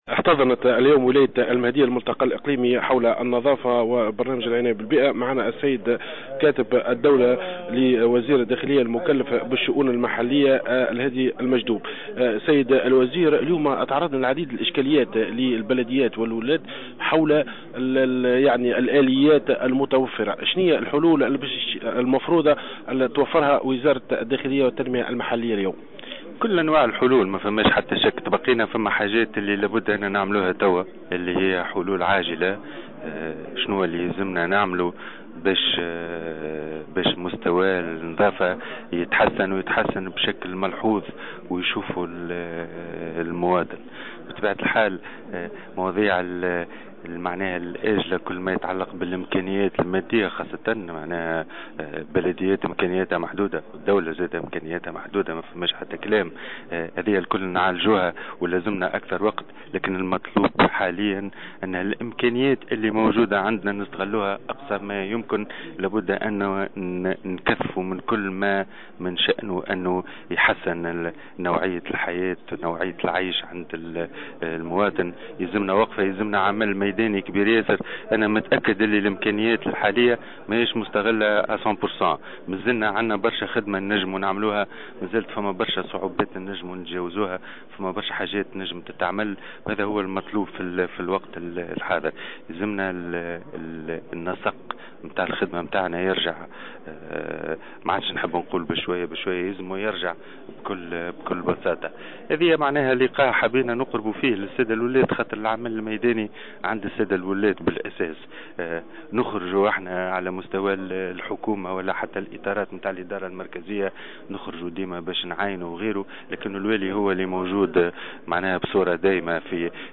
وقال في تصريح لمراسلنا بالمهدية
على هامش ملتقى رسمي حول البرنامج الوطني للنظافة